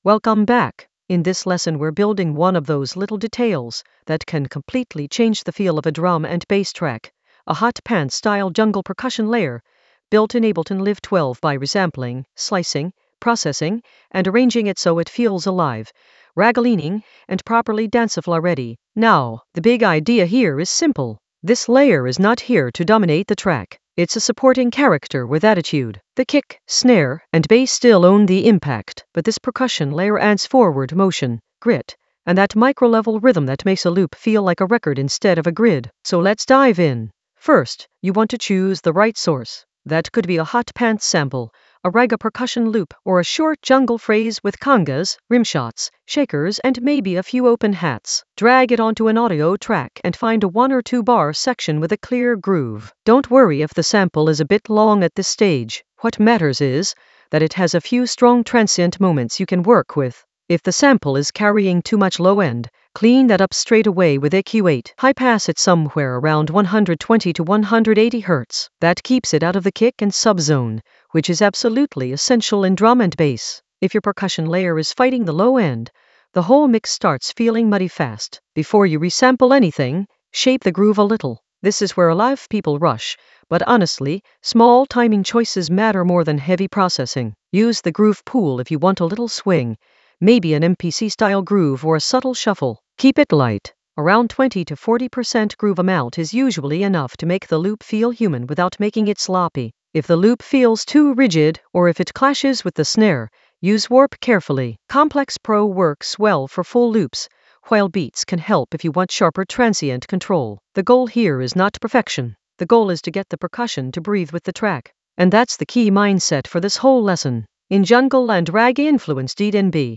An AI-generated intermediate Ableton lesson focused on Hot Pants jungle percussion layer: resample and arrange in Ableton Live 12 in the Ragga Elements area of drum and bass production.
Narrated lesson audio
The voice track includes the tutorial plus extra teacher commentary.